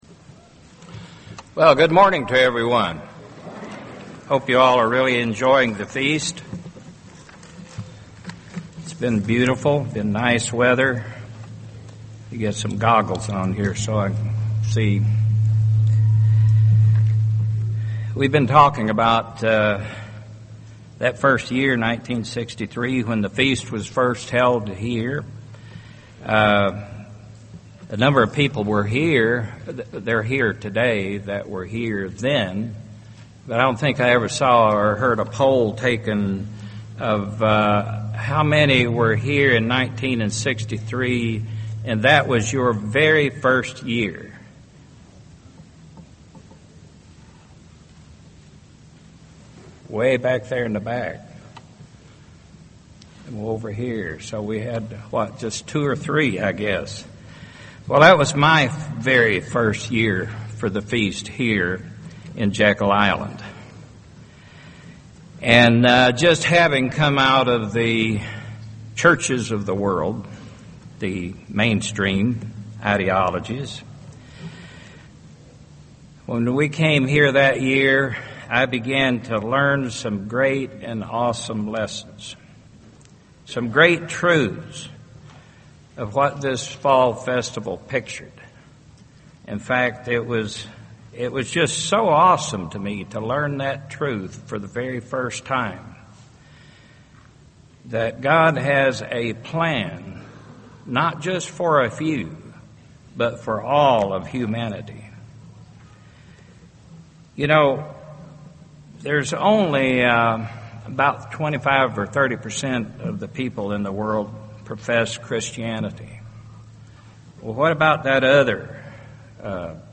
This sermon was given at the Jekyll Island, Georgia 2015 Feast site.